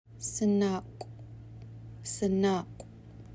To learn the correct pronunciation of Sen̓áḵw, please click here.